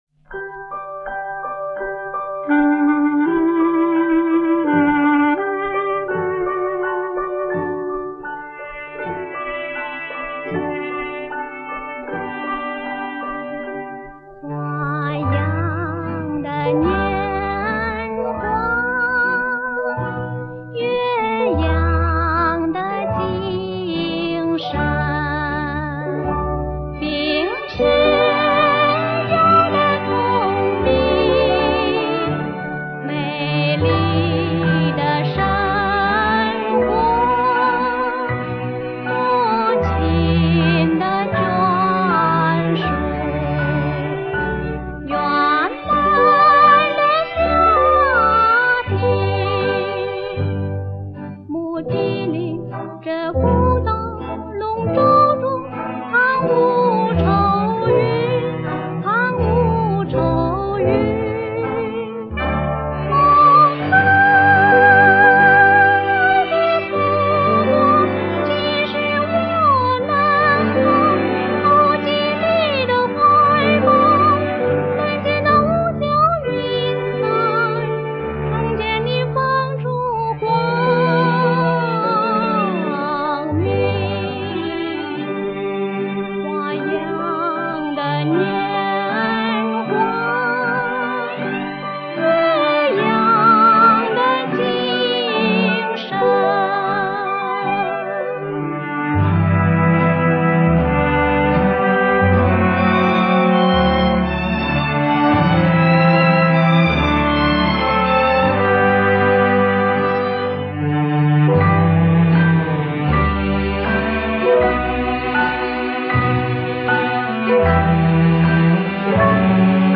为了让各位SIAV老友能在原汁原味的“上海老歌”中感受“忆•上海”，我们坚持所有曲目原人原唱。